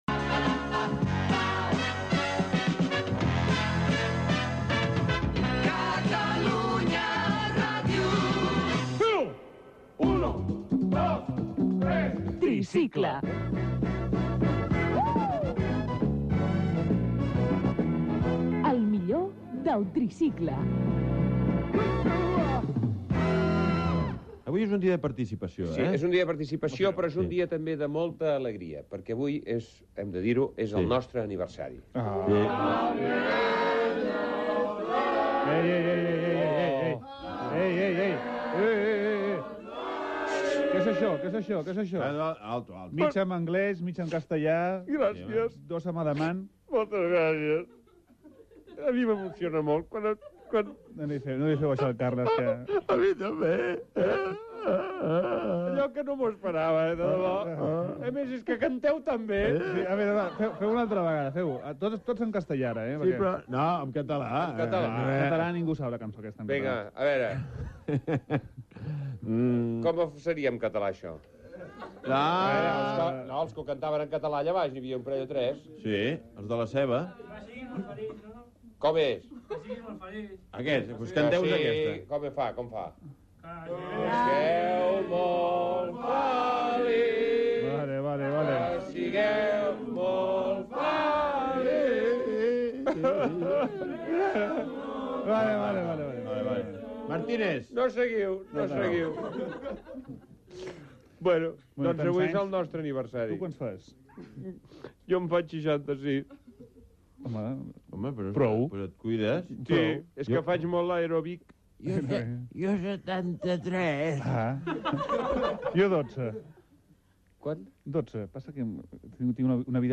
Indicatiu de l'emissora, careta del programa, cant i diàleg sobre l'aniversari i l'edat
Entreteniment